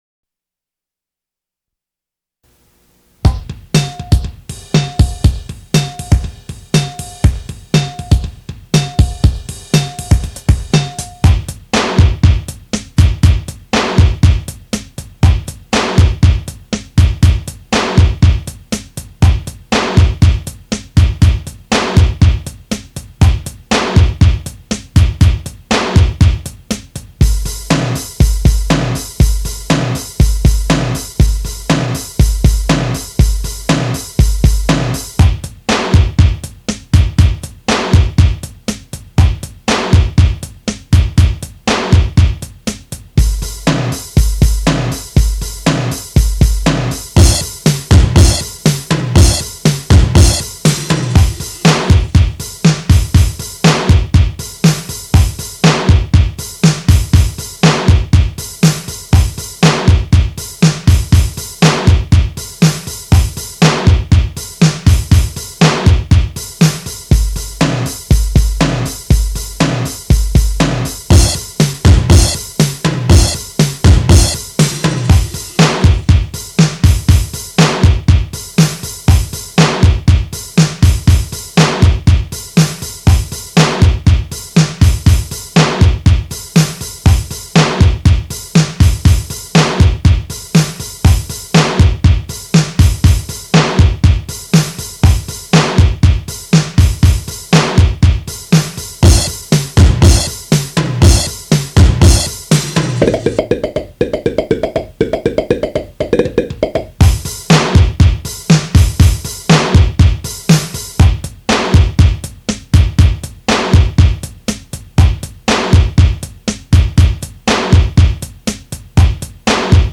Create your own rap song.